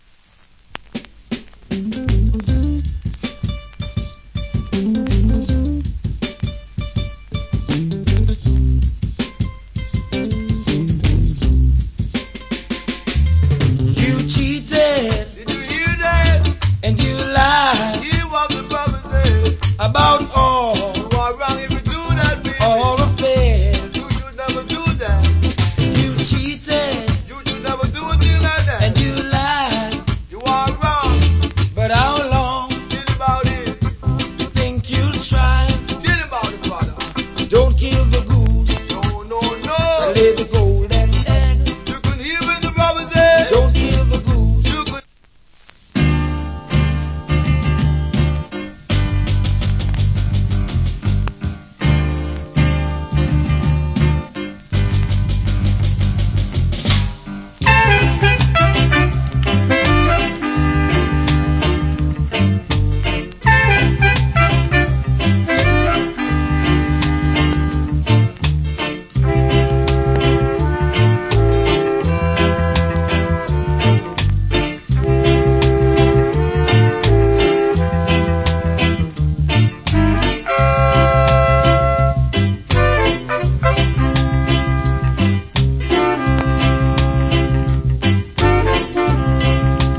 Killer DJ.